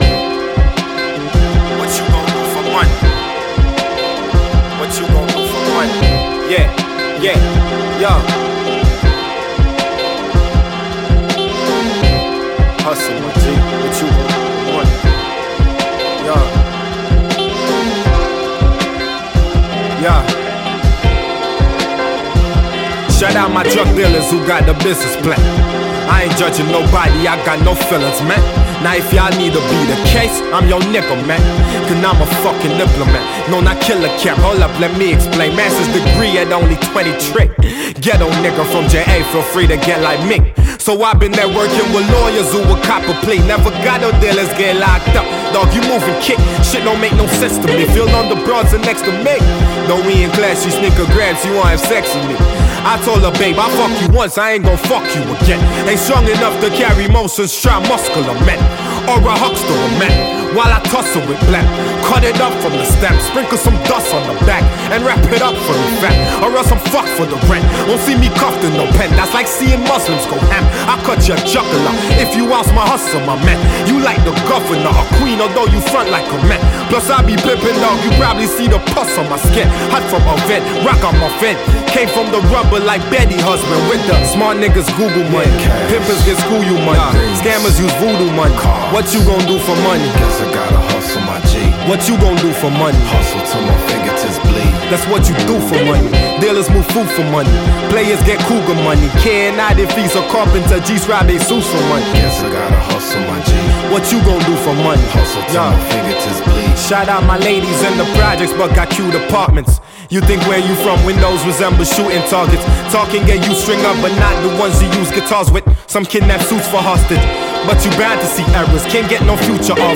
hip hop
melodic instrumentation
raspy voice
The variation in flows and cadences are deeply engaging